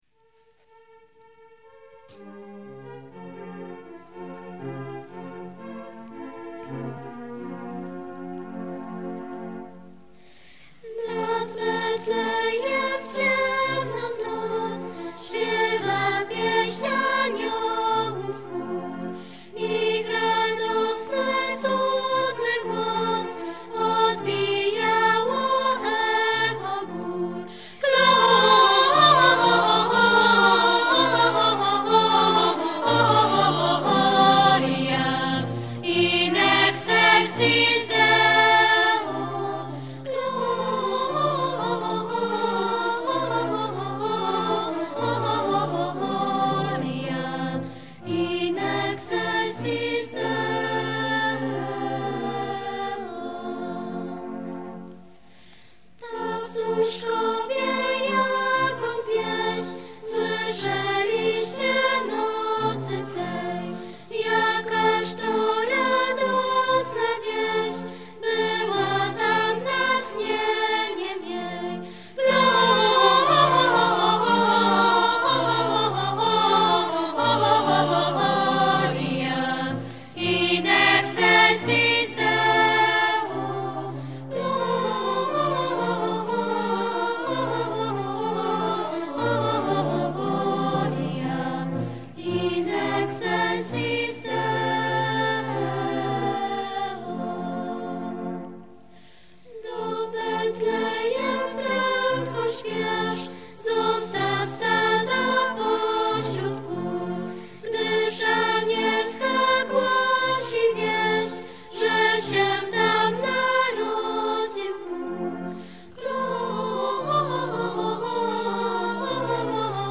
Nagrania live, utwory w formacie mp3 (96kbps),
zarejestrowane na koncertach w Jasieniu i w Domecku.